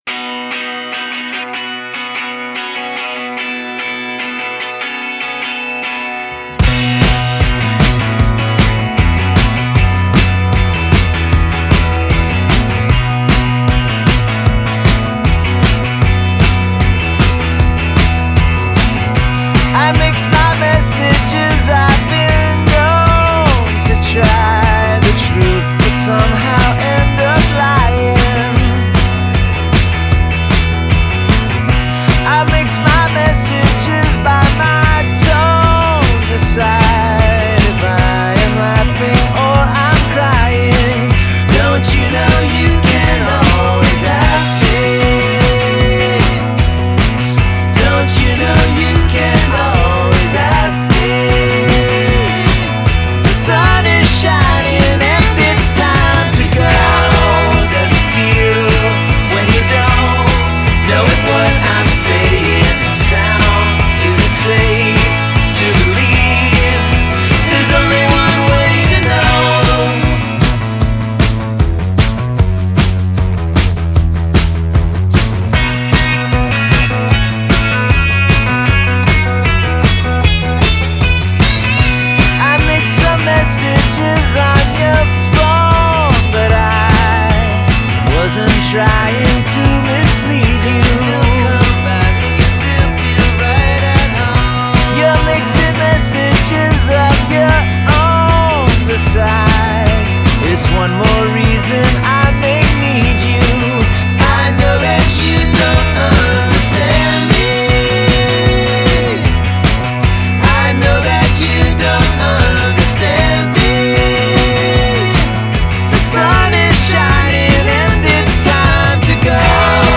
poprock single